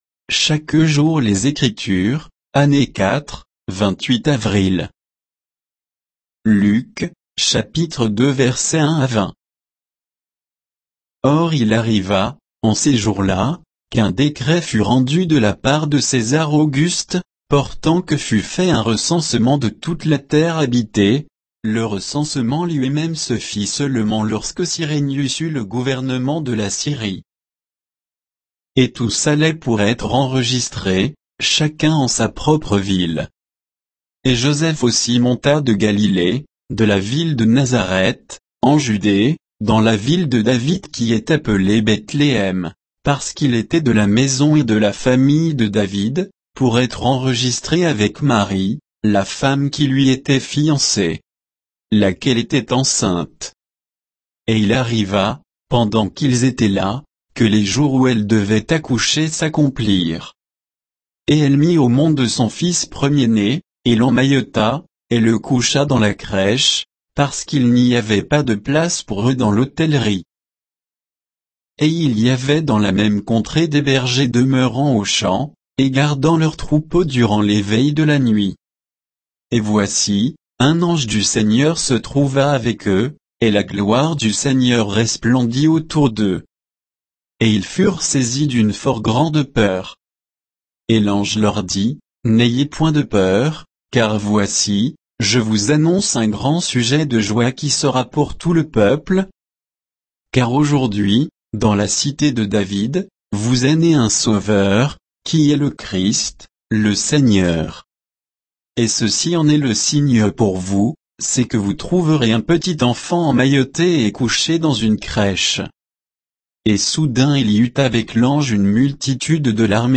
Méditation quoditienne de Chaque jour les Écritures sur Luc 2, 1 à 20